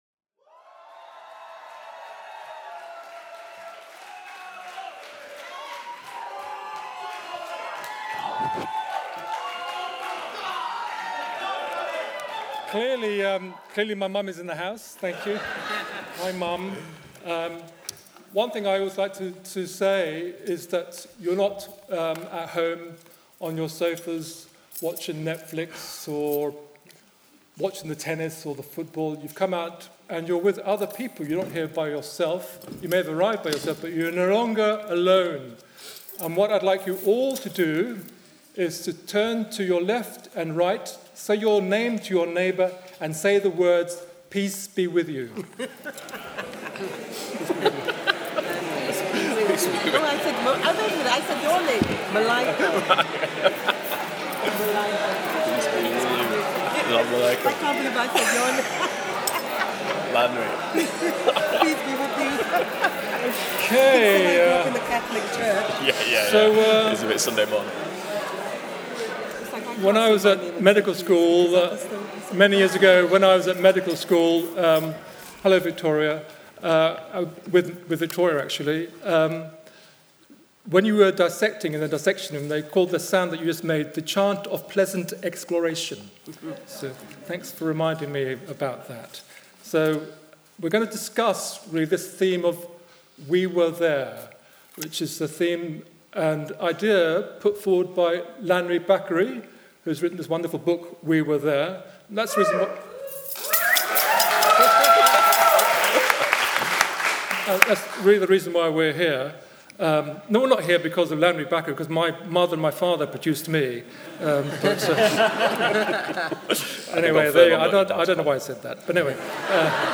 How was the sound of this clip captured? We Were There at the Southbank Centre Well the north is rising with northern writers, filmmakers and musicians headed south with WritersMosaic to show London the wealth of its talent and vibrancy, by taking over the Southbank Centre for We Were There , a night of music, film, poetry and prose.